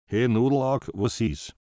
"He, Nudlaug, wos is?", ist die etwas forscher formulierte Version - beides sind Beispiele, wie der Computer in Zukunft auf eine nicht erfolgte Eingabe reagieren könnte.
nudlaug_66036.mp3